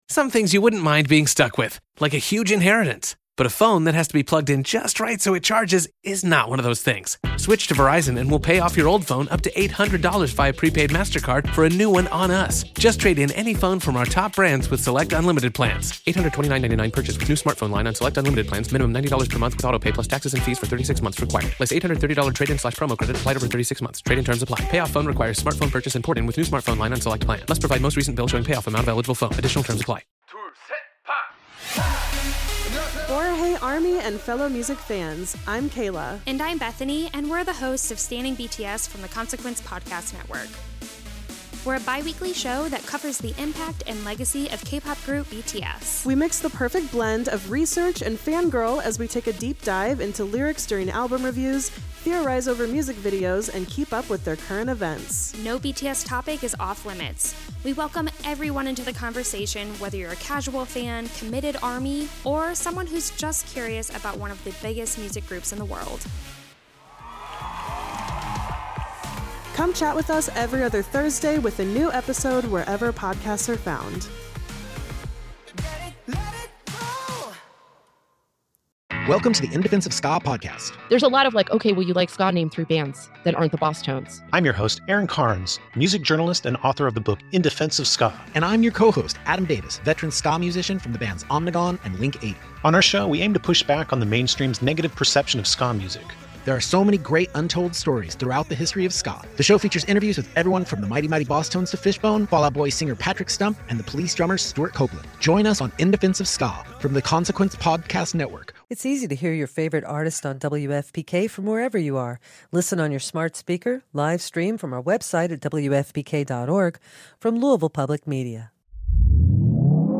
an interview series